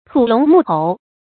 土龙沐猴 tǔ lóng mù hóu
土龙沐猴发音